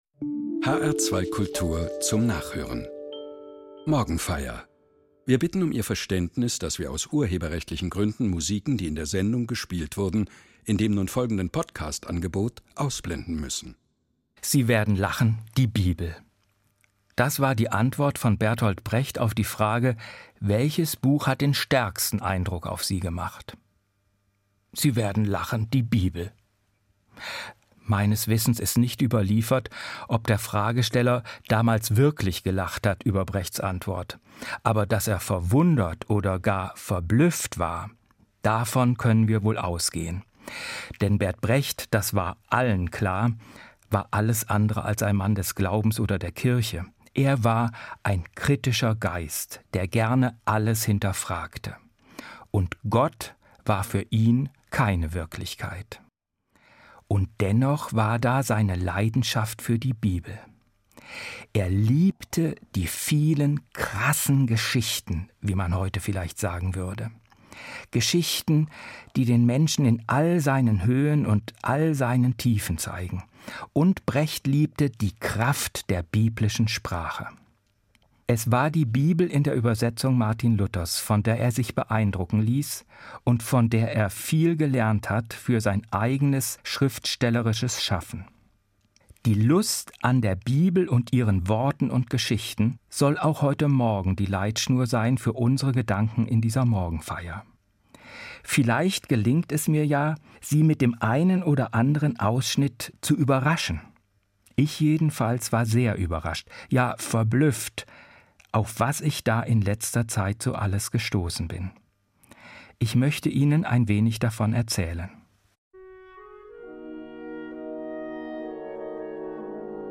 Evangelischer Pfarrer